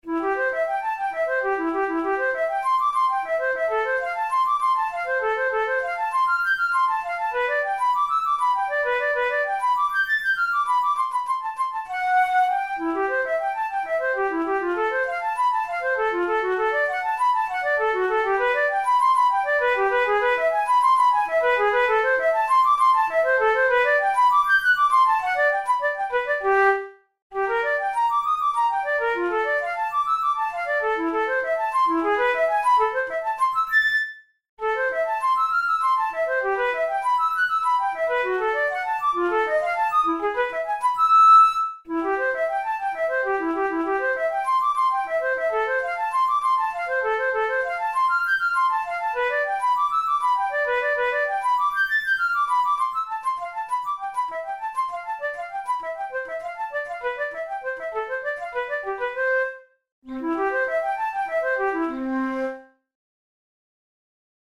Categories: Classical Etudes Written for Flute Difficulty: intermediate